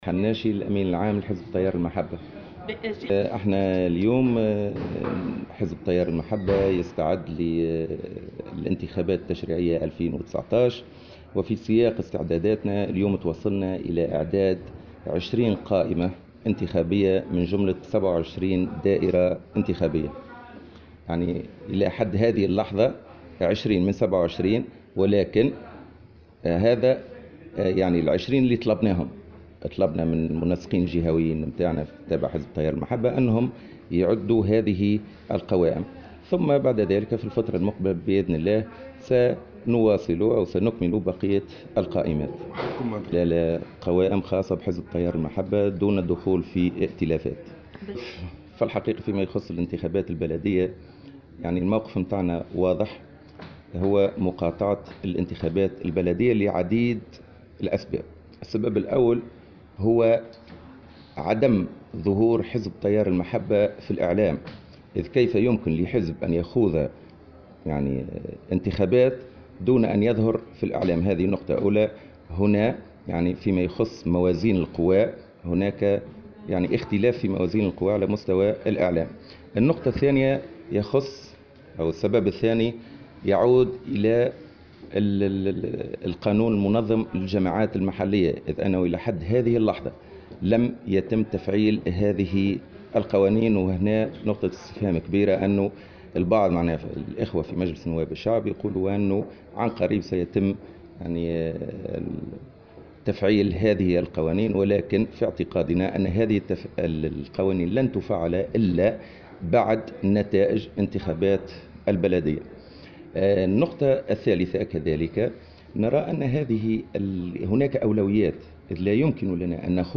وجاءت تصريحاته على هامش اجتماع المجلس الوطني للحزب في دورته الثانية اليوم الأحد بالعاصمة.